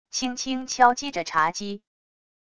轻轻敲击着茶几wav音频